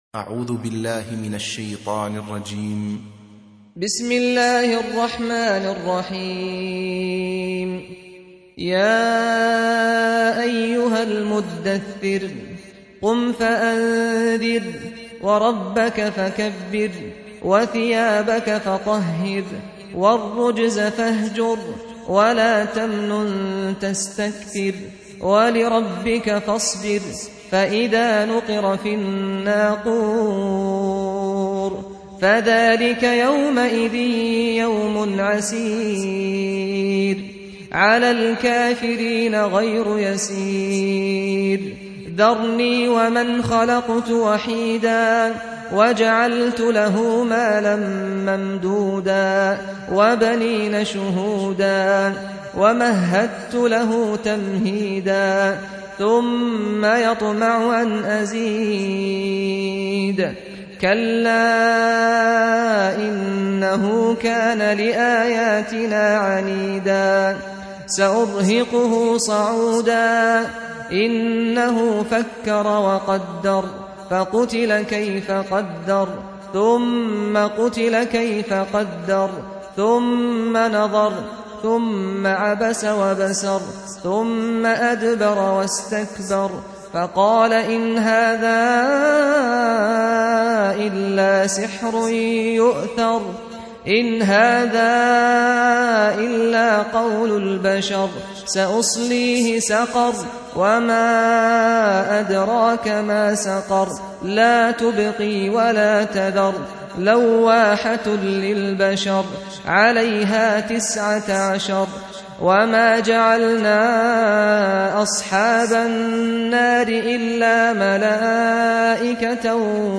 74. Surah Al-Muddaththir سورة المدّثر Audio Quran Tarteel Recitation
Surah Repeating تكرار السورة Download Surah حمّل السورة Reciting Murattalah Audio for 74.